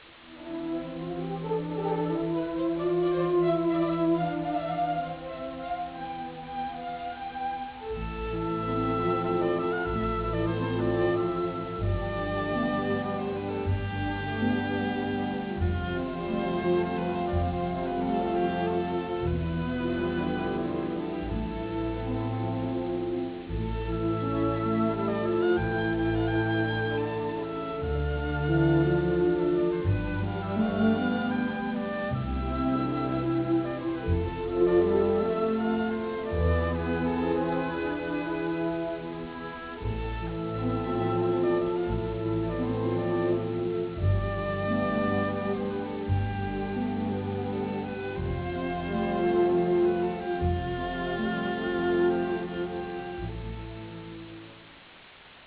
Musica in stile medioevale
Original track music